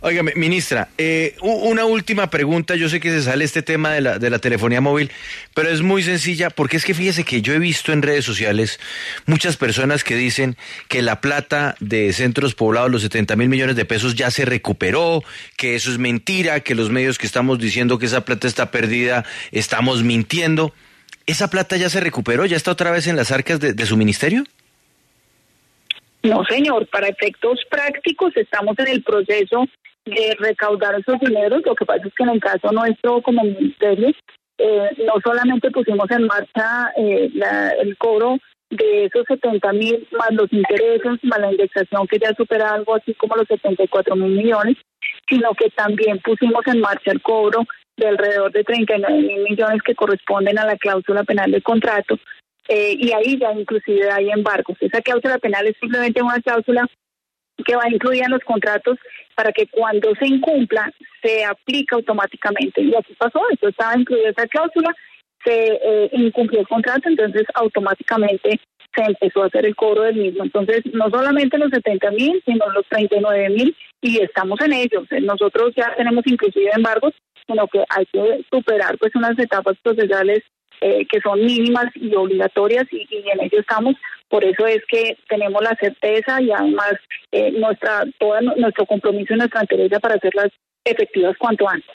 Escuche la entrevista completa con la ministra de las TIC, Carmen Ligia Valderrama